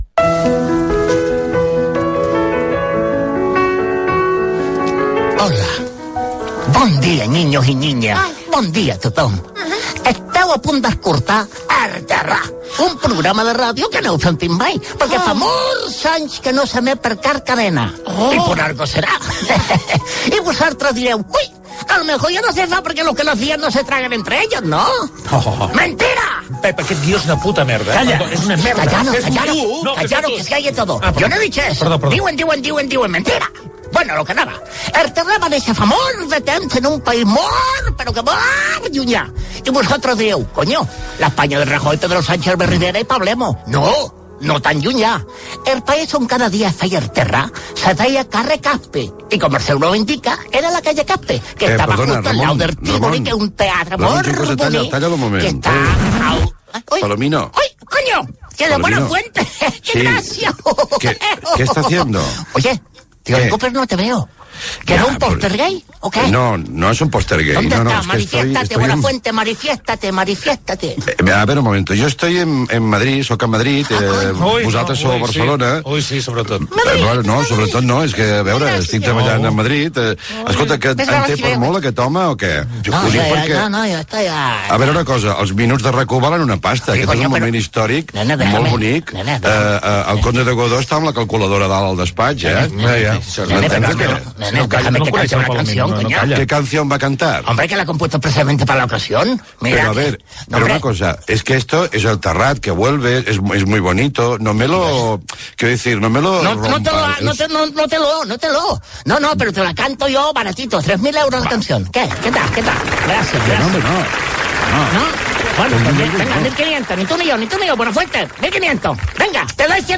Careta del programa, presentació i equip.
Entreteniment